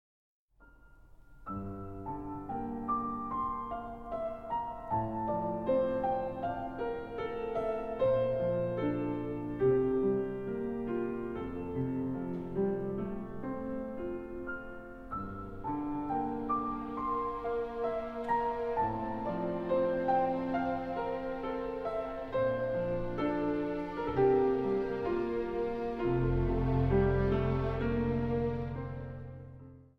Zang | Gemengd koor